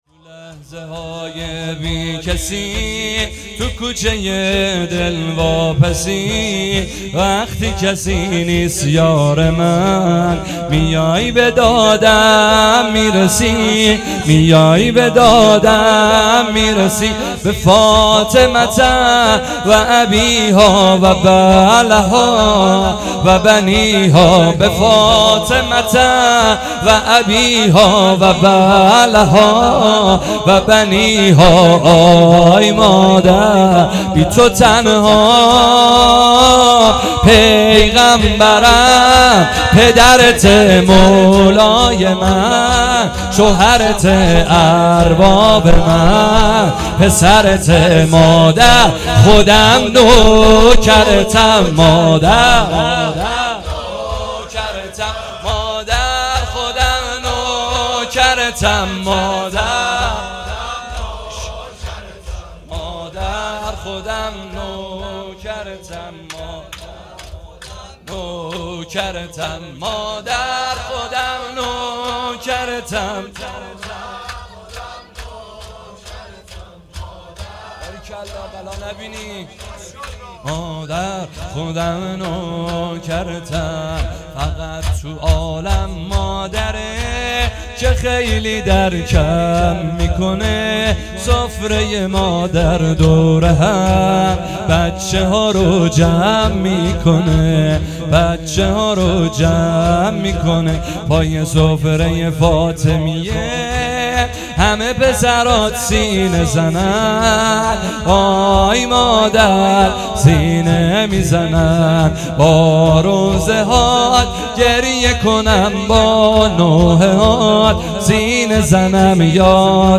شور
اقامه عزای شهادت حضرت زهرا سلام الله علیها _ دهه دوم فاطمیه _ شب سوم